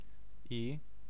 Ääntäminen
Ääntäminen phoneme: IPA : /aɪ/ US : IPA : [aɪ] phoneme: IPA : /ɪ/ Haettu sana löytyi näillä lähdekielillä: englanti Käännös Ääninäyte Substantiivit 1. i I on sanan I taipunut muoto.